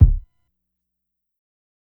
KICK_INSANITY.wav